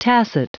Prononciation du mot tacit en anglais (fichier audio)